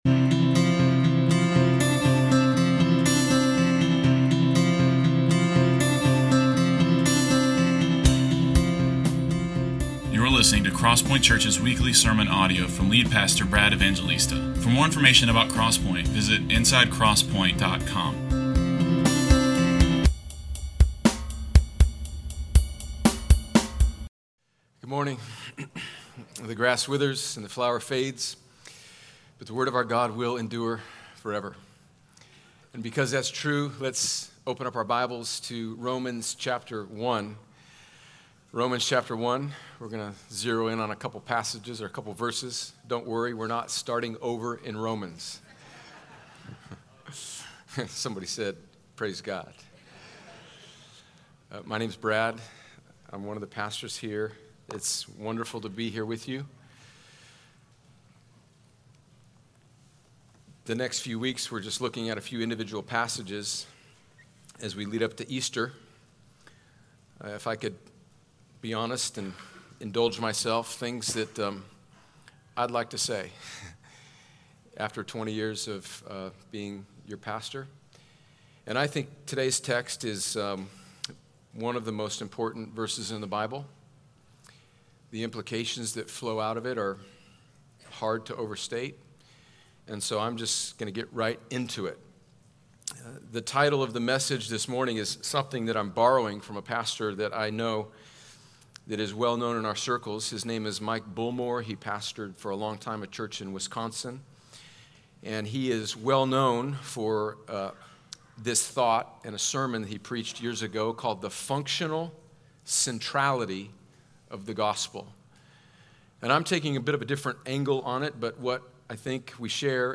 The sermons of CrossPointe Church in Columbus, Ga.